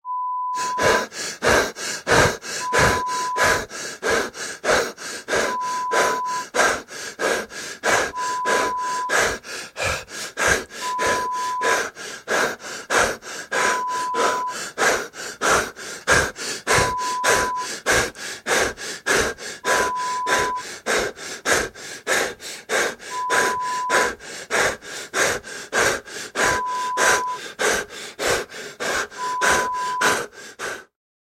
Respiração ofegante = Respiración jadeante
Sonido de hombre con respiración jadeante, acelerada. Acompaña a dicho sonido un pitido espaciado y constante no relacionado con dicha actividad
jadeo
Sonidos: Acciones humanas
Sonidos: Voz humana